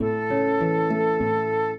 flute-harp
minuet7-12.wav